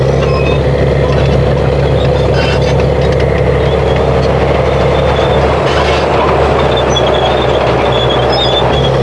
tank2.wav